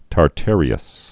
(tär-târē-əs)